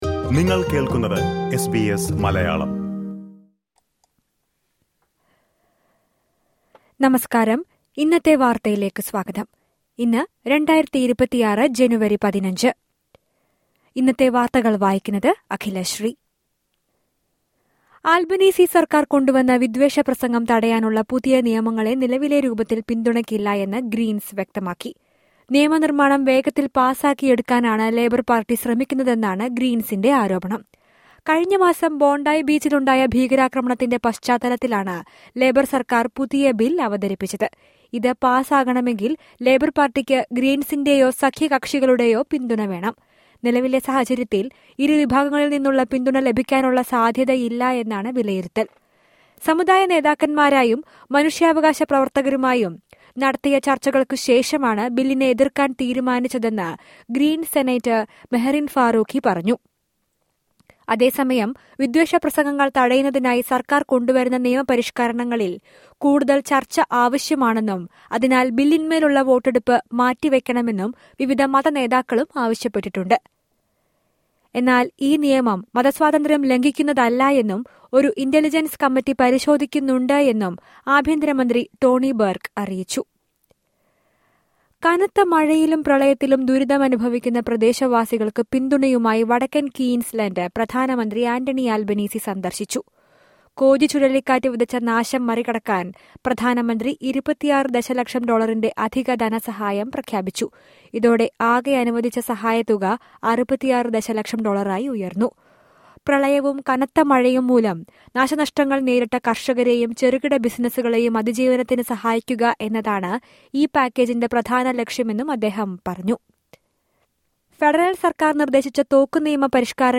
2025 ജനുവരി 15ലെ ഓസ്ട്രേലിയയിലെ ഏറ്റവും പ്രധാന വാർത്തകൾ കേൾക്കാം...